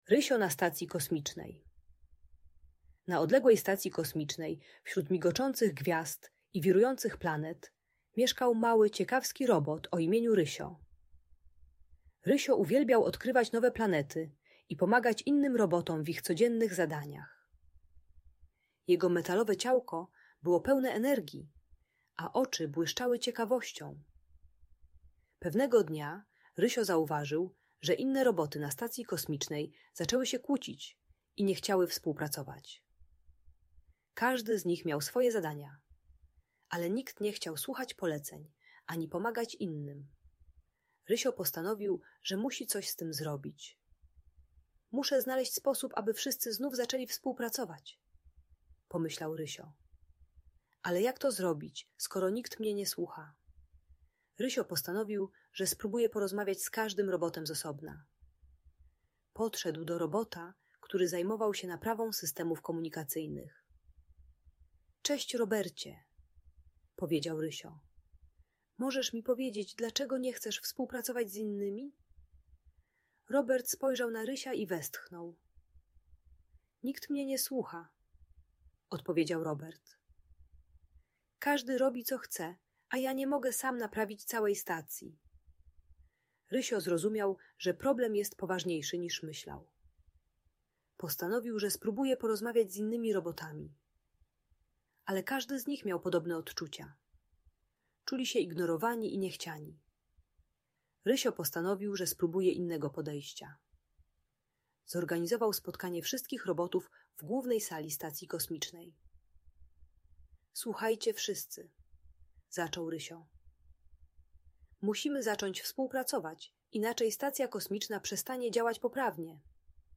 Historia o Rysiu na Stacji Kosmicznej - Audiobajka dla dzieci